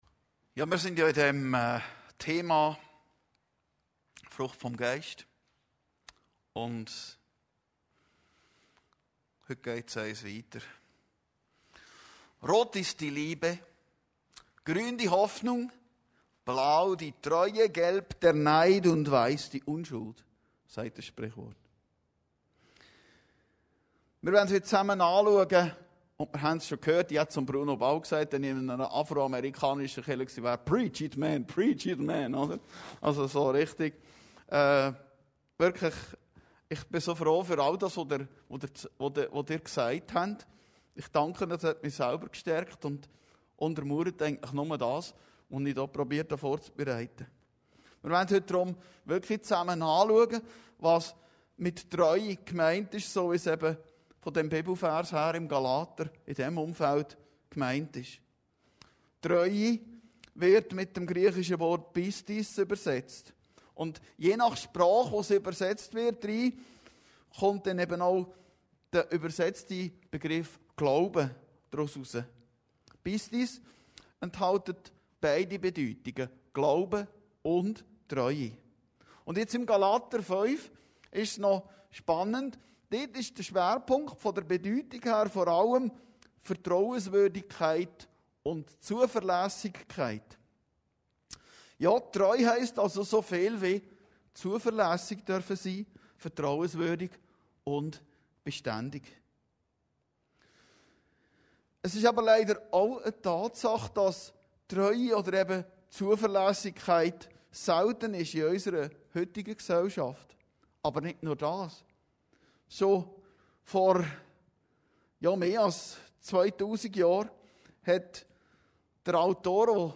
Predigten Heilsarmee Aargau Süd – die Treue